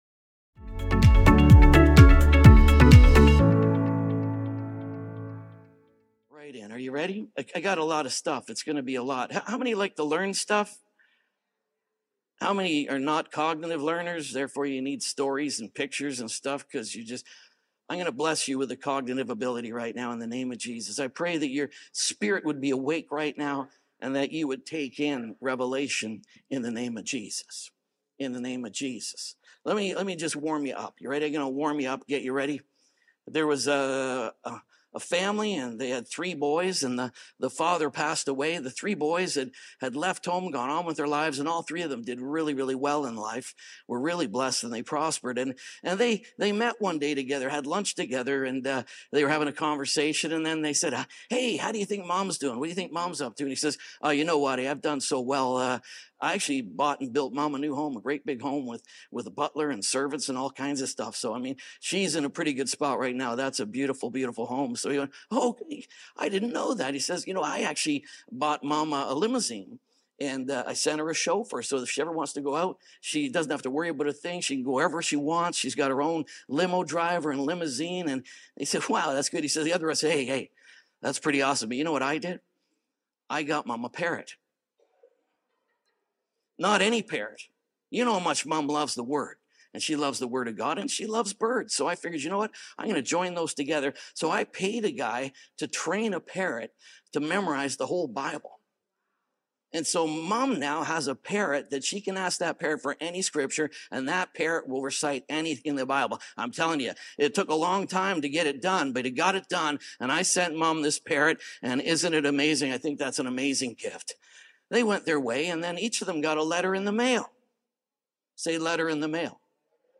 Once-And-For-All-|-WASHED-Series-|-SERMON-ONLY.mp3